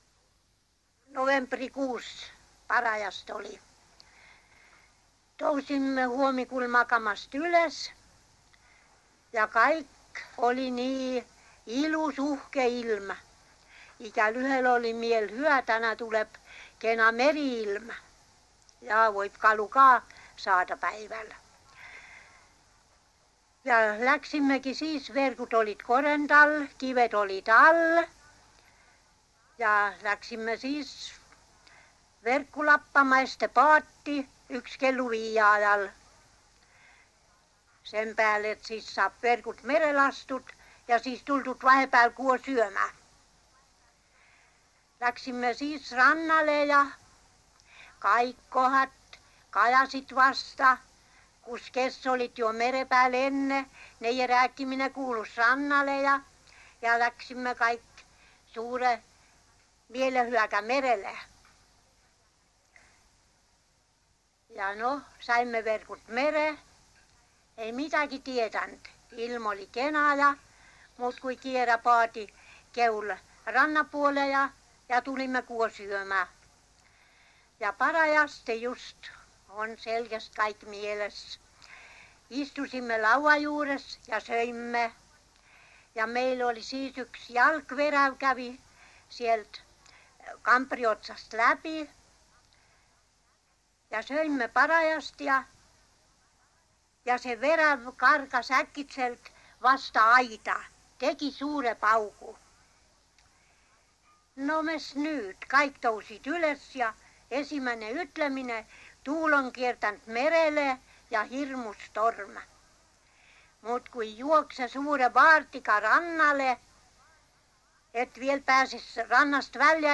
MurdekiikerKirderannikumurreRKuusalu